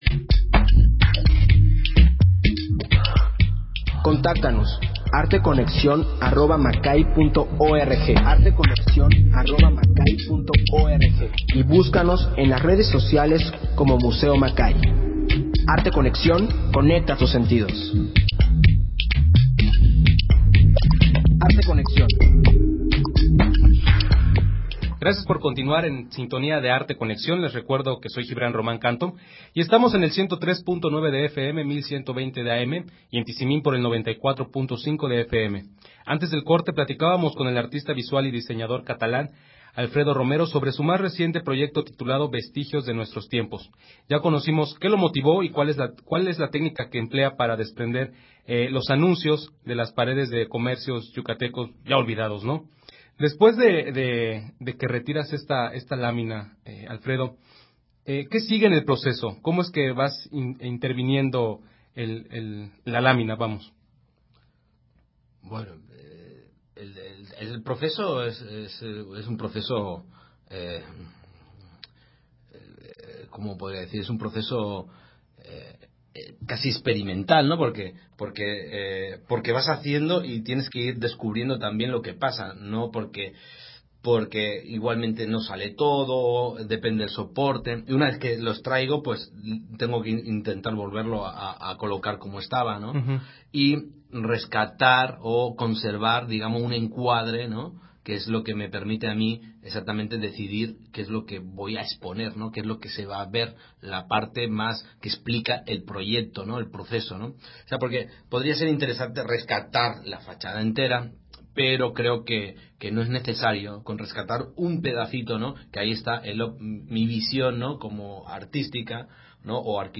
Emisión de Arte Conexión transmitida el 16 de marzo del 2017.